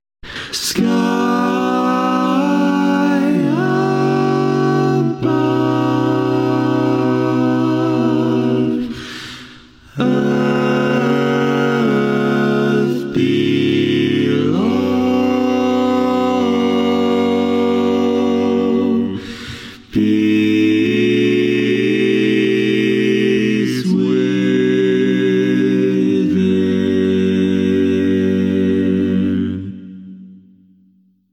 Key written in: G Major
How many parts: 4
Type: Barbershop
All Parts mix:
Learning tracks sung by